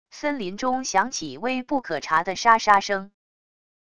森林中响起微不可察的沙沙声wav音频